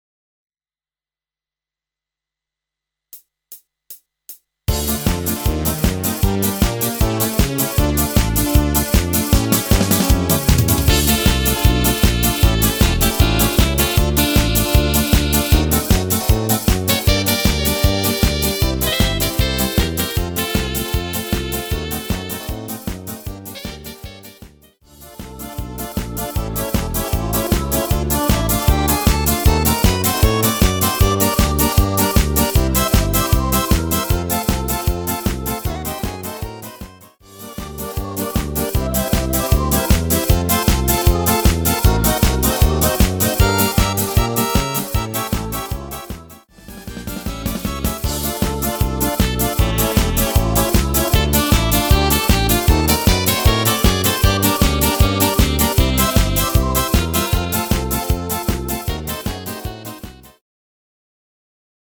Rubrika: Pop, rock, beat
Cikánská lidová